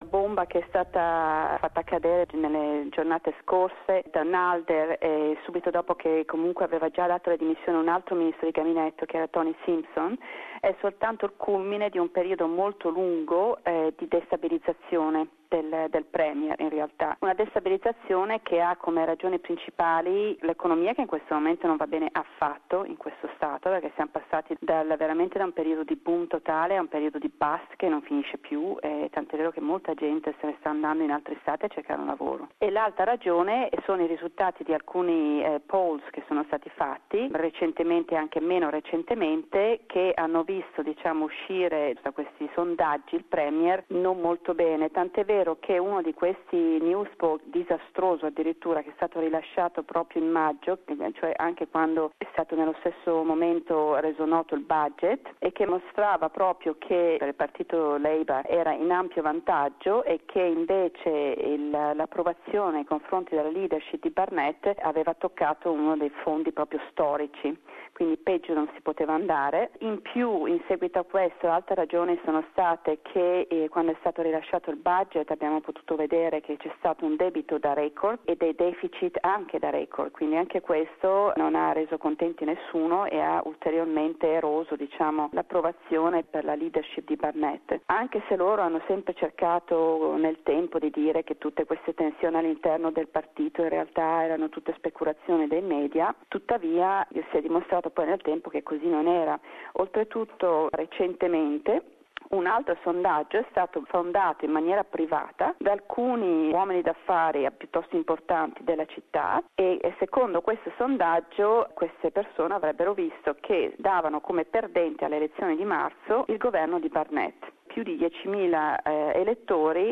news correspondent from Perth